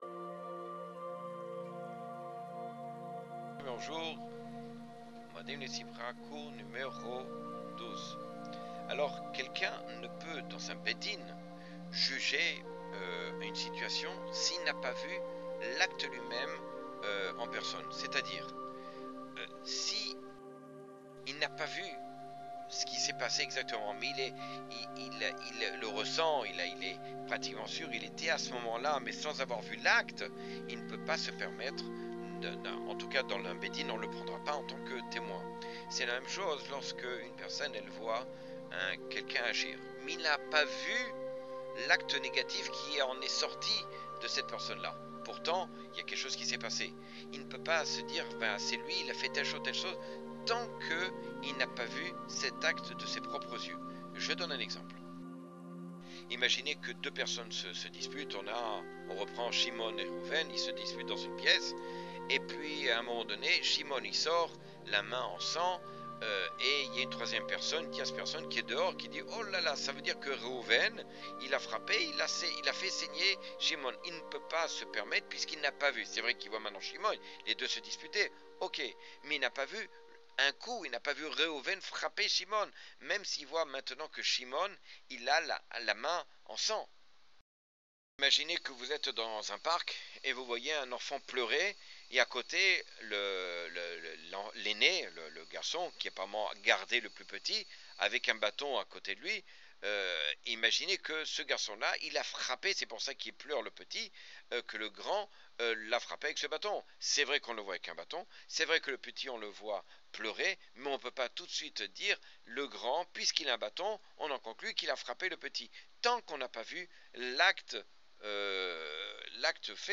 Cours 12 sur les lois de juger favorablement.